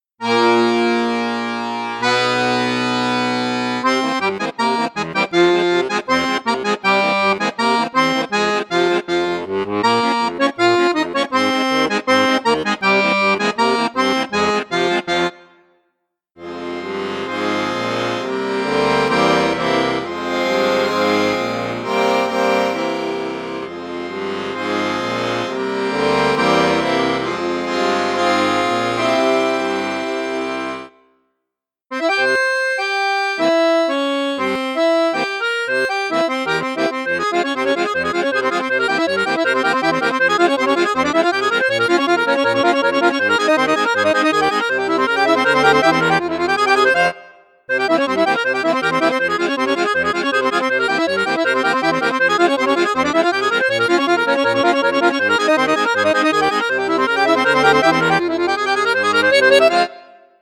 registre 16′ + 8′ (boite) + 4′ accord unisson